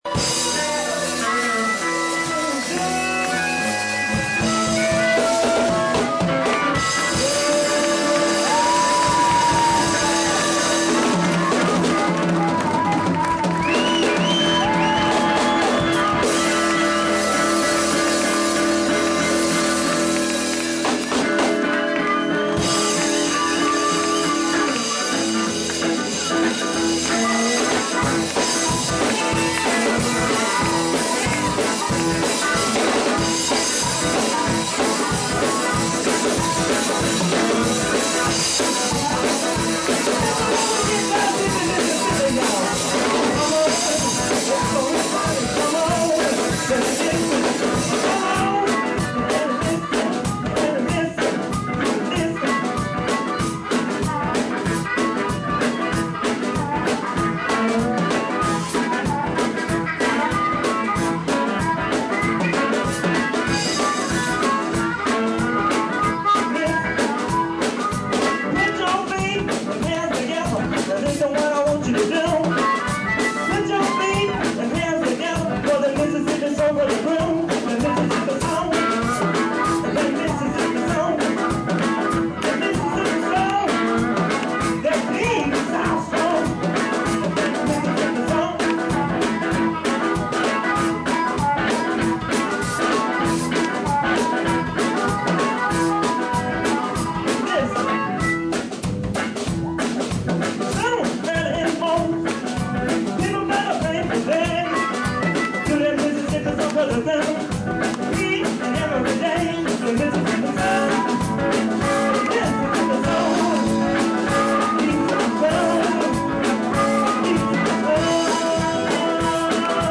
slid seamlessly from one tune to the next.